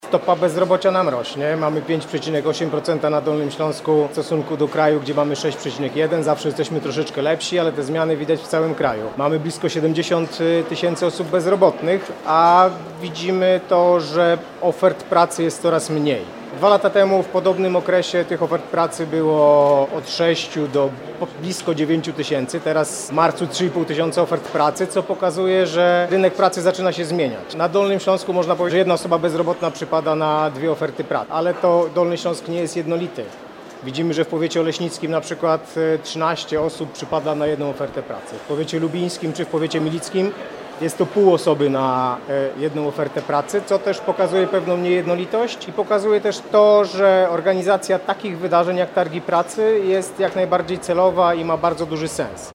– Z danych wynika, że na Dolnym Śląsku stopa bezrobocia wynosi obecnie 5,8% – podkreśla Bartosz Kotecki, dyrektor Wojewódzkiego Urzędu Pracy we Wrocławiu.